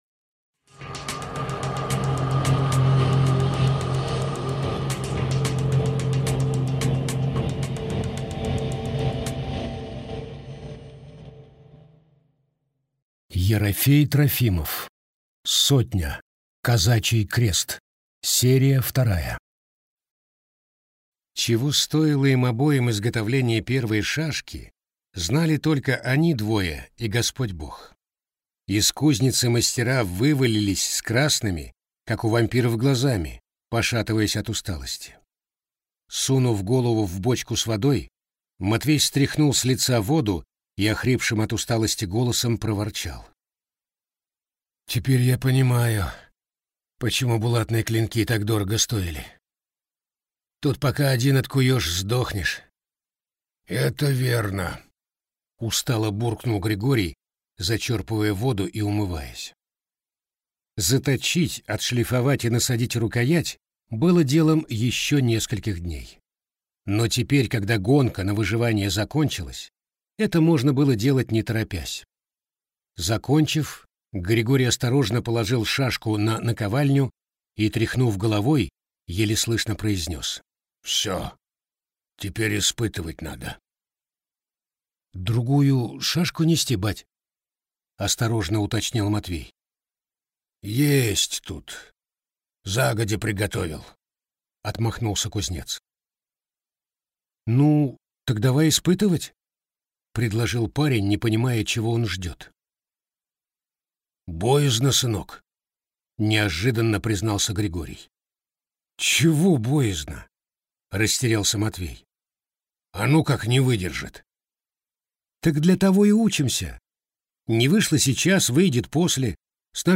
Аудиокнига «Последний».